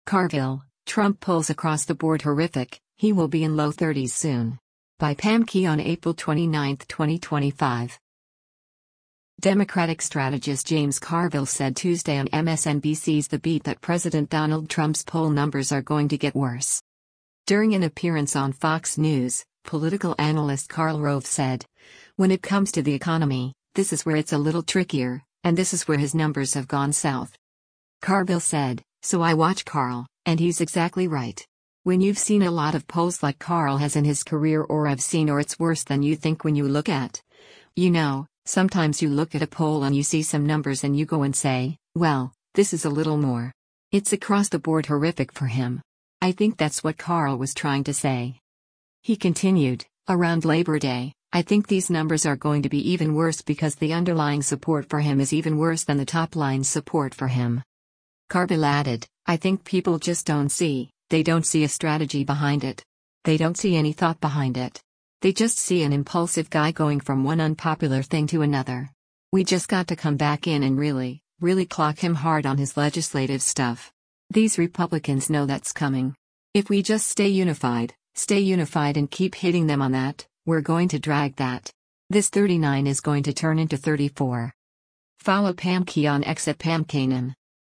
Democratic strategist James Carville said Tuesday on MSNBC’s “The Beat” that President Donald Trump’s poll numbers are going to get worse.